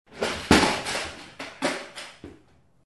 Звуки стула
Шум опрокинутого стула